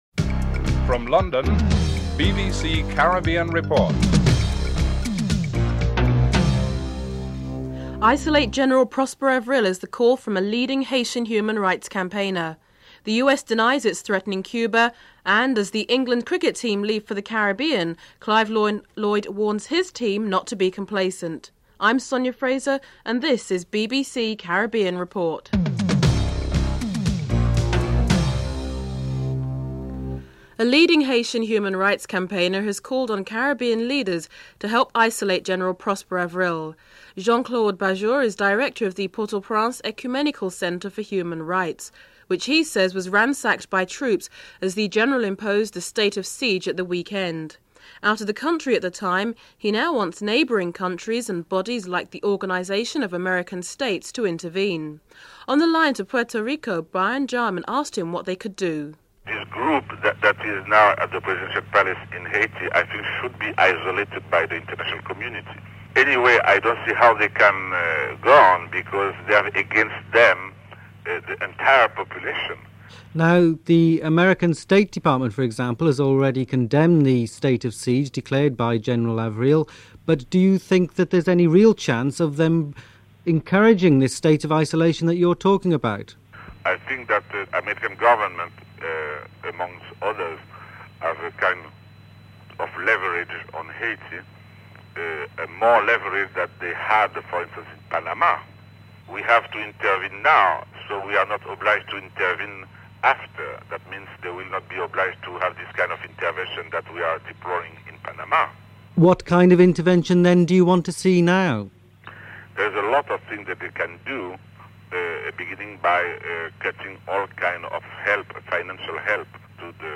The segment also provides excerpts from the opening ceremony of the 14th Commonwealth Games in New Zealand, highlighting the upcoming 100 meters race between Linford Christie and Jamaican sprinter, Ray Stewart.
3. Lifting of Travel restrictions for OECS citizens. Interview with Eugenia Charles, Prime Minister of Dominica (03:15-05:45)
7. Sporting segment.